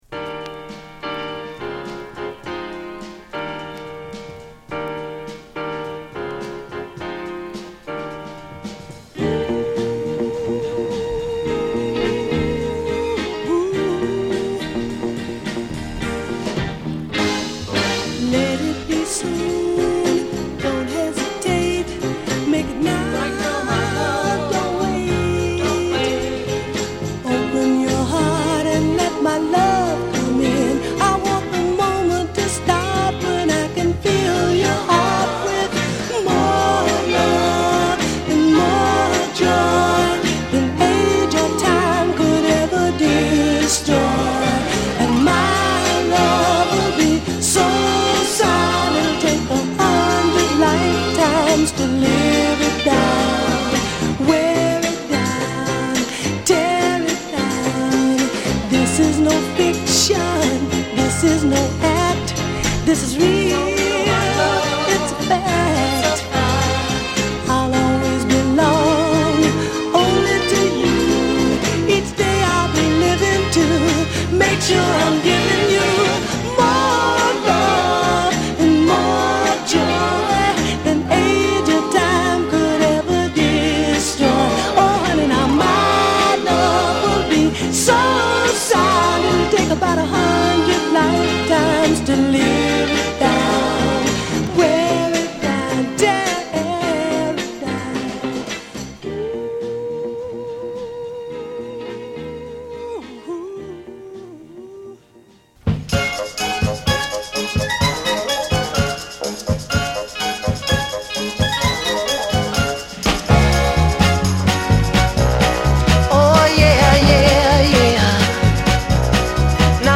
期待を裏切らない60'sソウルを披露！